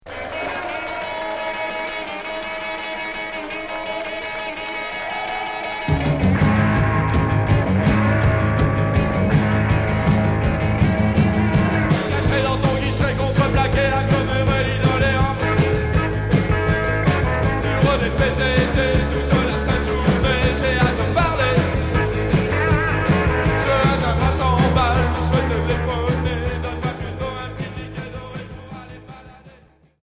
(live)
Rock